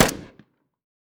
Shot_v2.wav